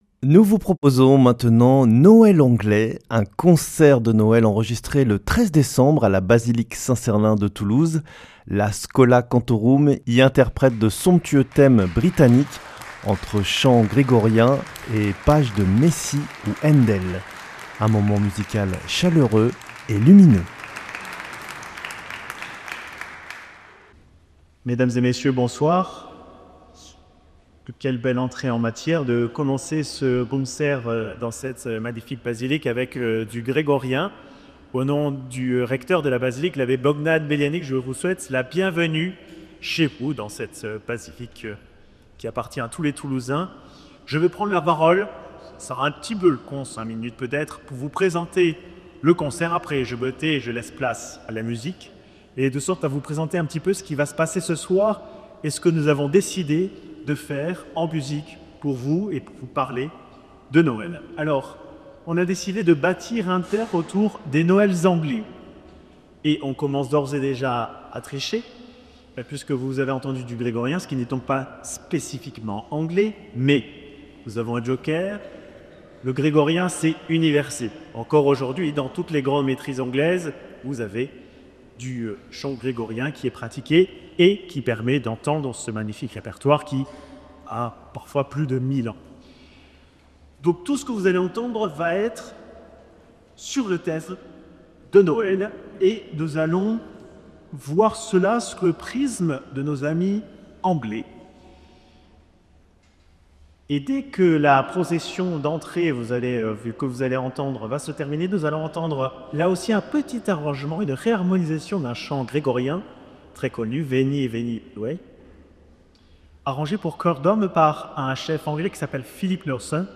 En ce 25 décembre, Radio Présence vous propose un moment musical rare et lumineux : le concert de Noël anglais donné à la Basilique Saint-Sernin de Toulouse et enregistré spécialement pour cette diffusion.
À travers la tradition des Christmas Carols, la Schola Cantorum de Saint-Sernin nous plonge au cœur de la spiritualité anglicane et de l’héritage choral britannique. Des chants de Noël populaires aux œuvres sacrées plus élaborées, ce programme fait résonner la joie, la douceur et l’espérance propres à la fête de la Nativité.
Dans l’acoustique majestueuse de la basilique, les voix du chœur, soutenues par l’orgue, célèbrent la naissance du Christ selon la grande tradition musicale anglaise, entre ferveur, simplicité et élévation spirituelle.